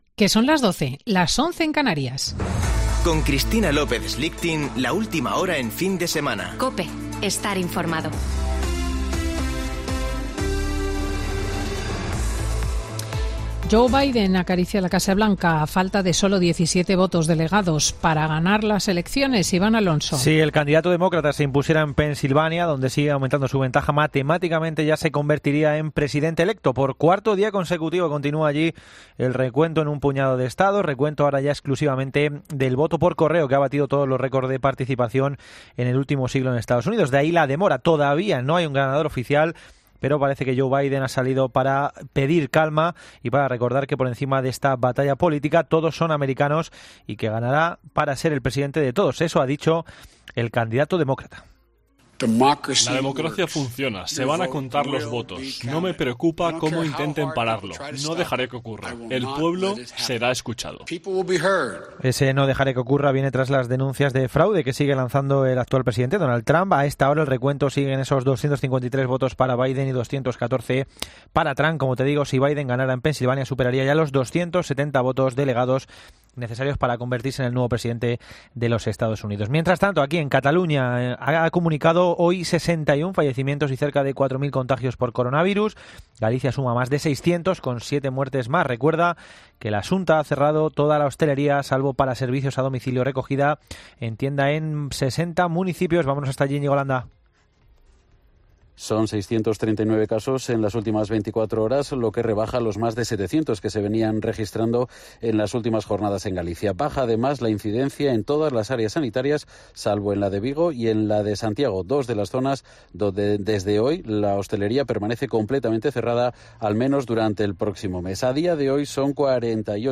Boletín de noticias de COPE del 7 de Noviembre de 2020 a las 12.00 horas